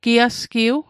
Pronunciation guide: gi·as·kiw